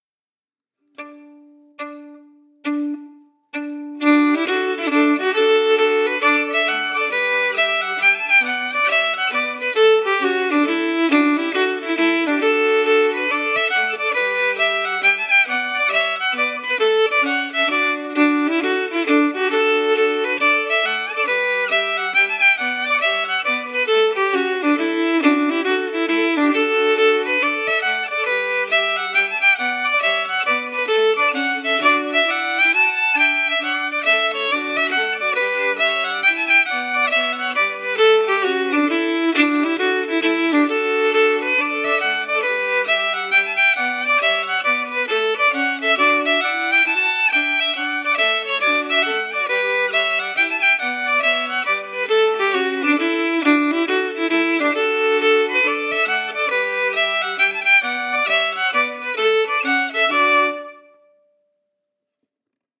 Key: D-major
Form: Hornpipe
Melody emphasis
Genre/Style: Irish hornpipe